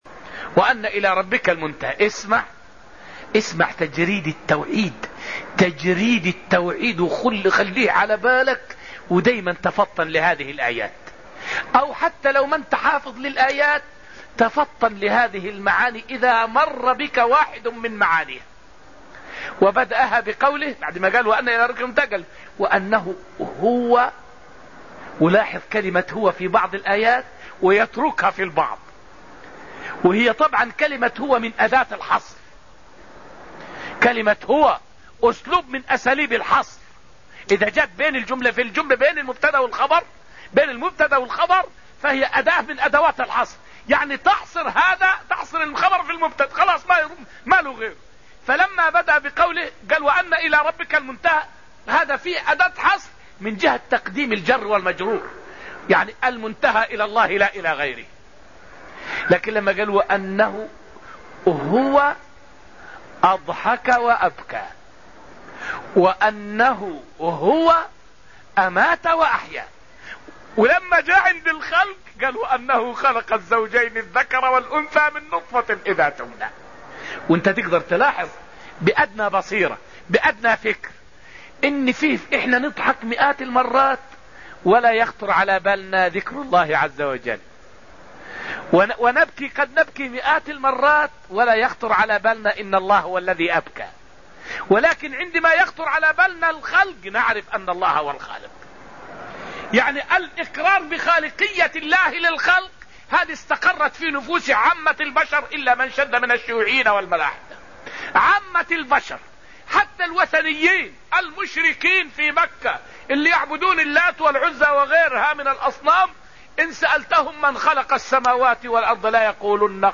فائدة من الدرس الرابع عشر من دروس تفسير سورة النجم والتي ألقيت في المسجد النبوي الشريف حول دلالة {هو} في قوله تعالى {وأنه هو أضحك وأبكى وأنه هو أمات وأحيا}.